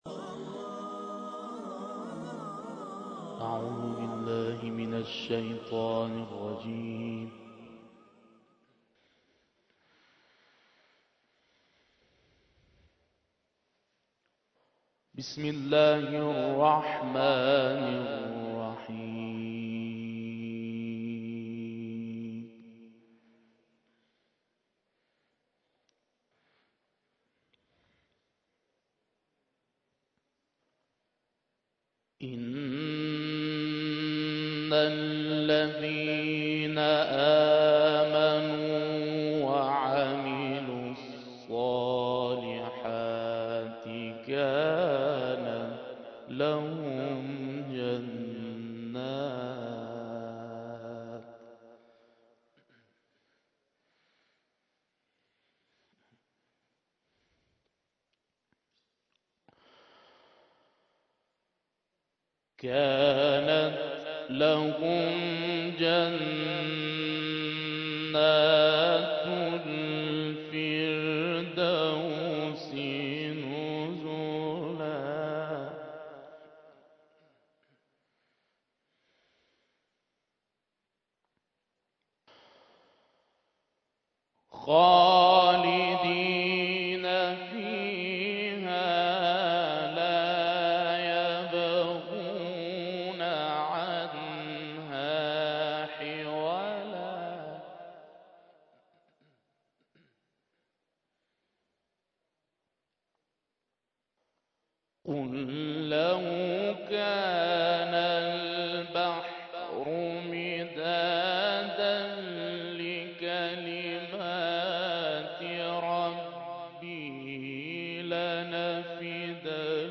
تلاوت جدید
در افتتاحیه بخش خواهران چهلمین دوره مسابقات سراسری قرآن کریم اجرا کرد.